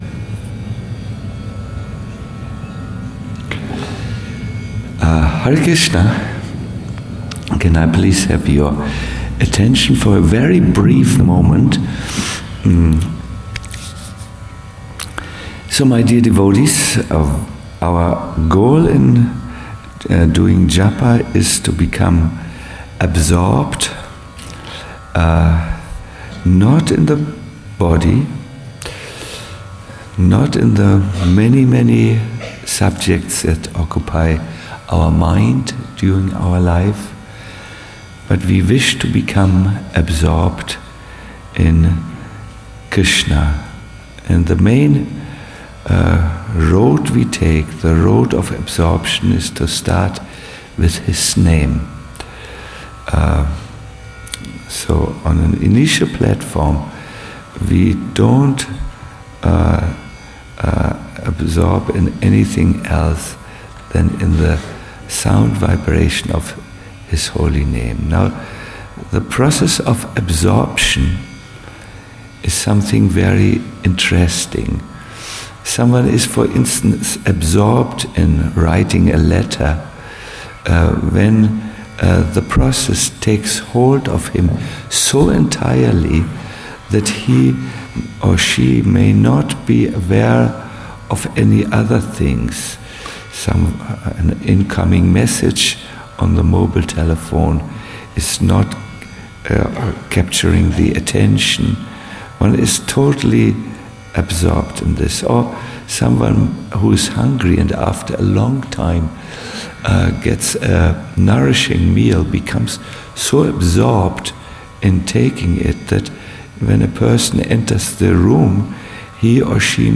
Govardhana Retreat Center